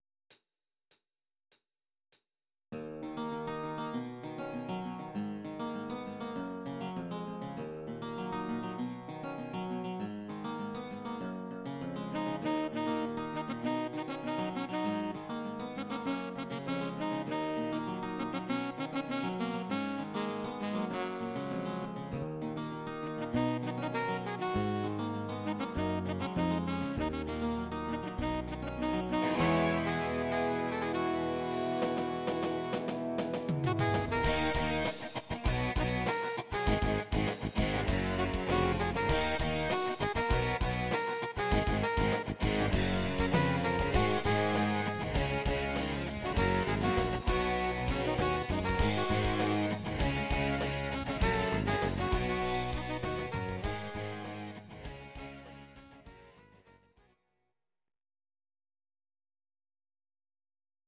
Audio Recordings based on Midi-files
Rock, 1990s